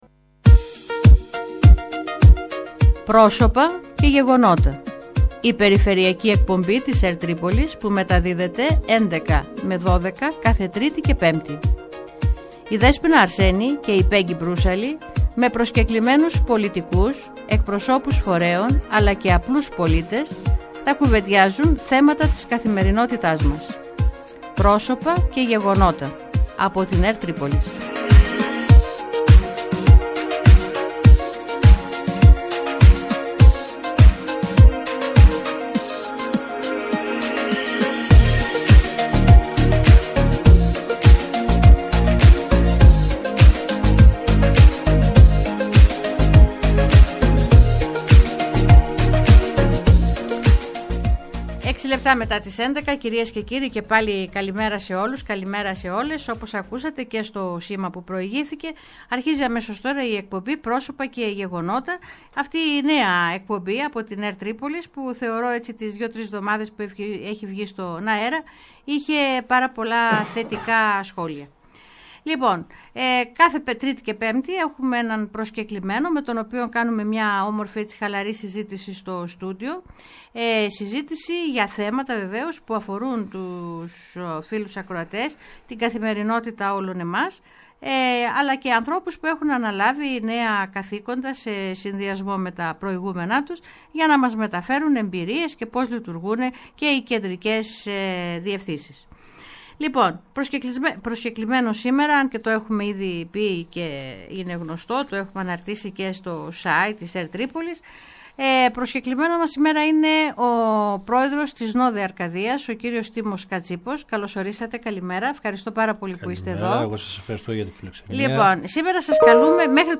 Στον φιλόξενο ραδιοθάλαμο της ΕΡΤ Τρίπολης